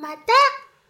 miya语音包重录